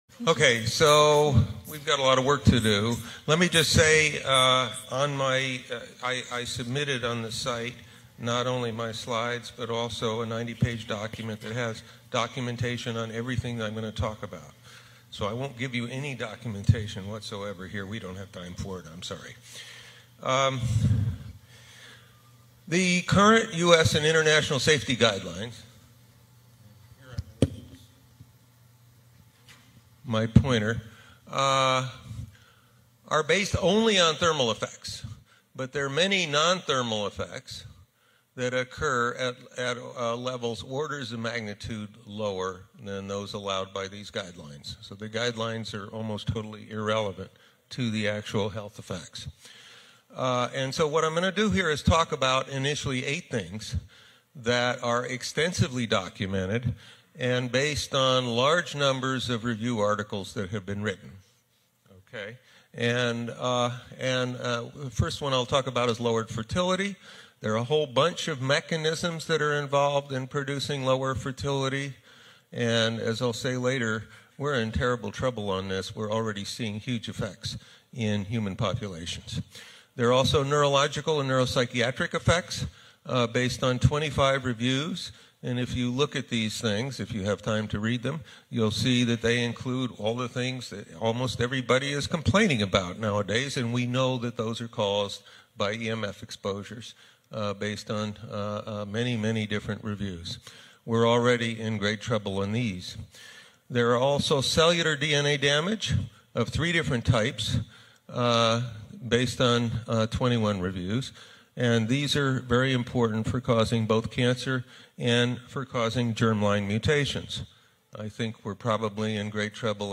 In this short presentation